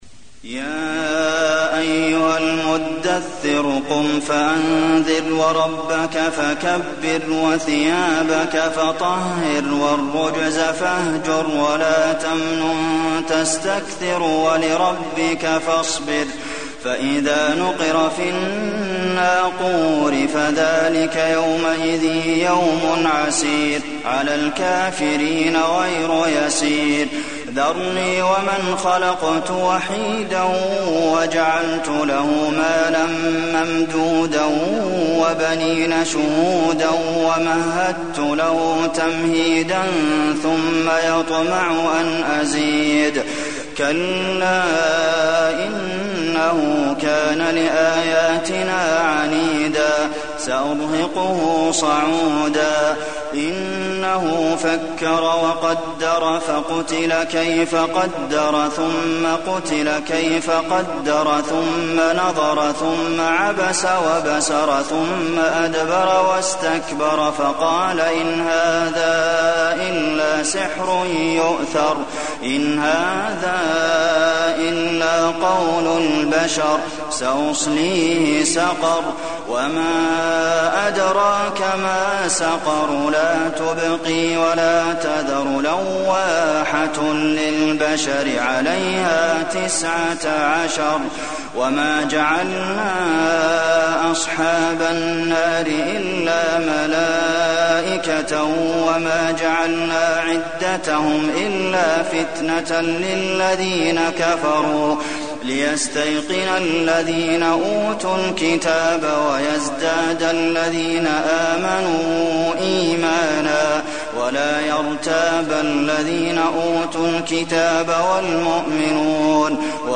المكان: المسجد النبوي المدثر